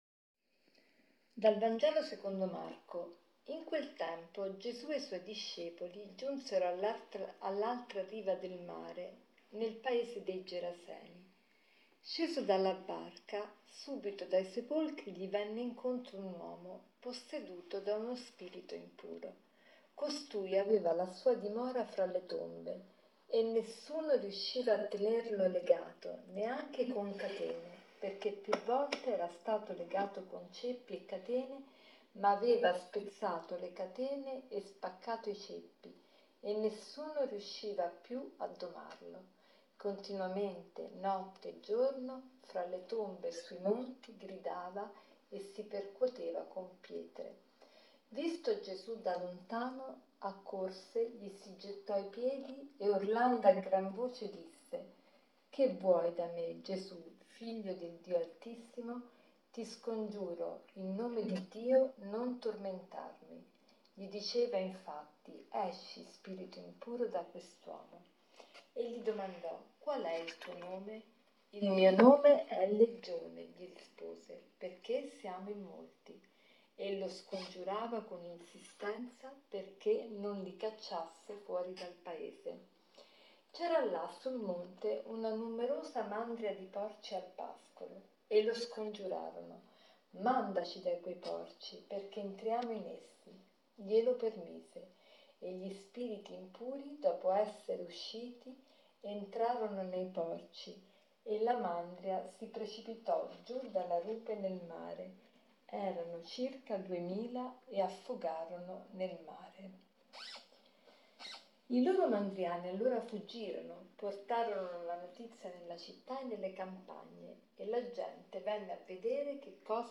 Commento al vangelo